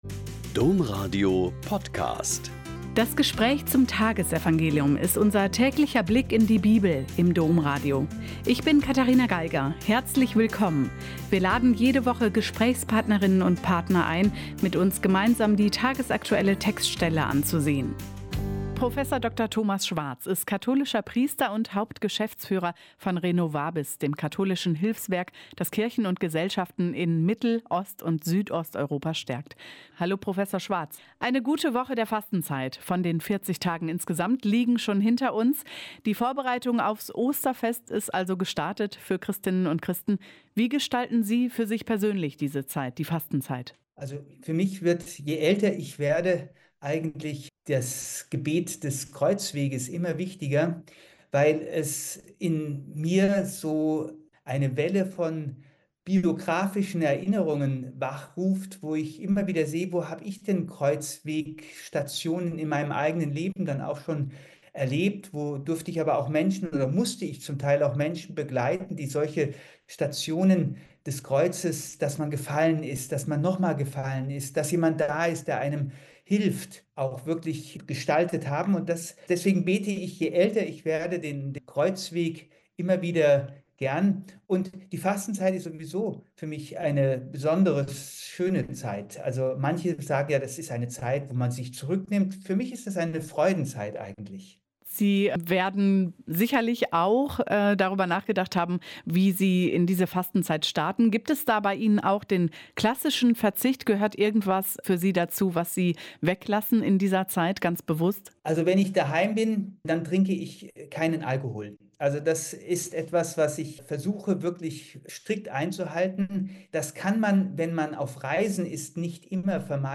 Mt 6,7-15 - Gespräch